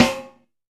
SNARE 029.wav